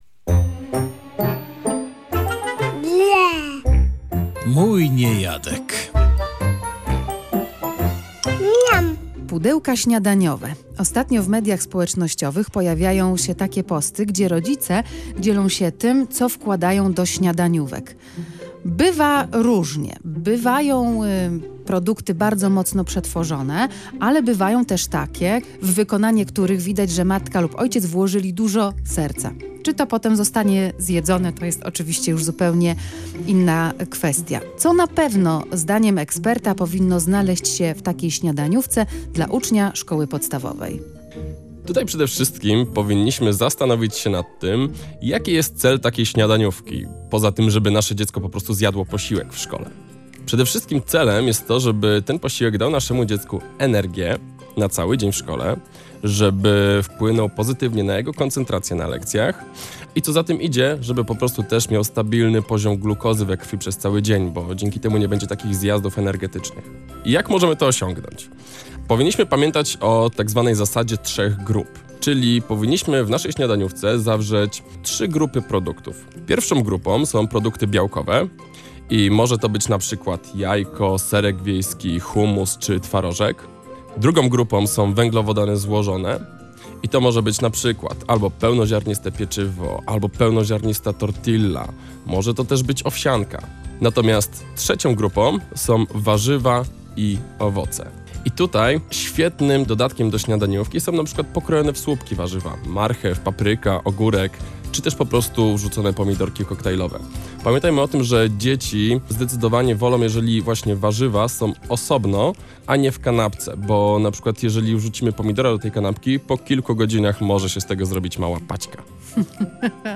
rozmawiała o tym z dietetykiem pediatrycznym